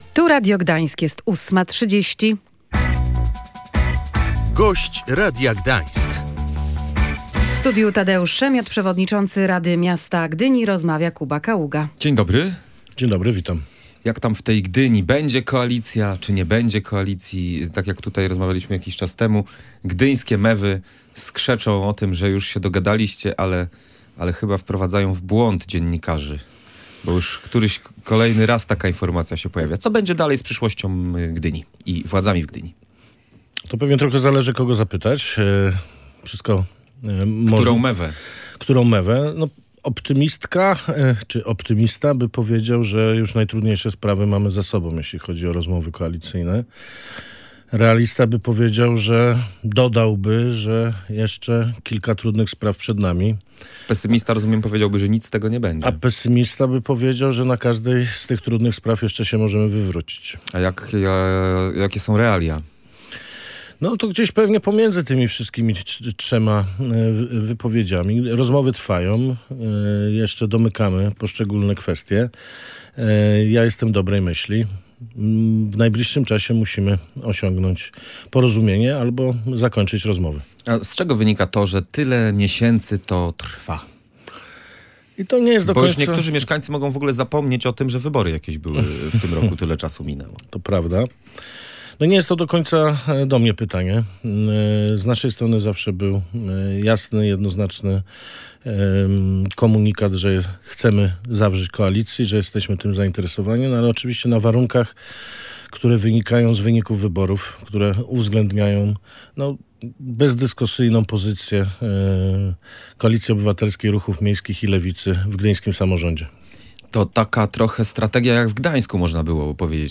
Nie możemy dojść do porozumienia w kilku kwestiach – mówił w Radiu Gdańsk przewodniczący Rady Miasta z Koalicji Obywatelskiej Tadeusz Szemiot.
Gość Radia Gdańsk